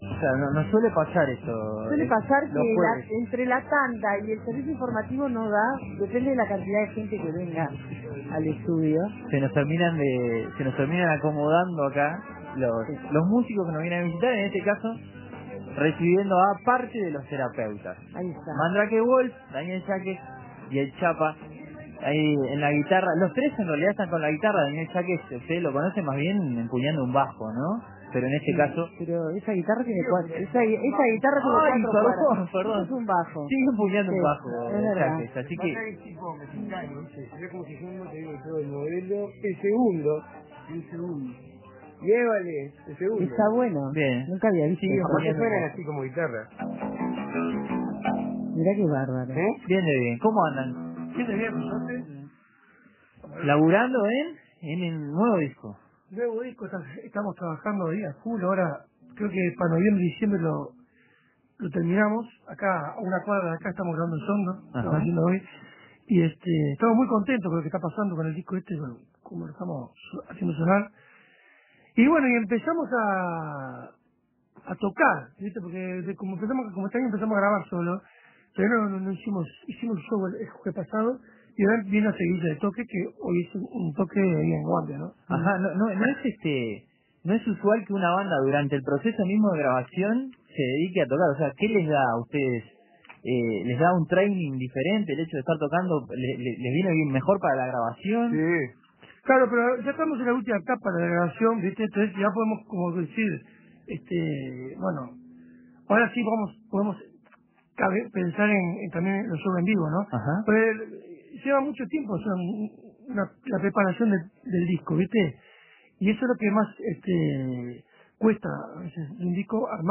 Tres integrantes del grupo uruguayo